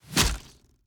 Free Fantasy SFX Pack
Bow Attacks Hits and Blocks
Bow Impact Hit 2.wav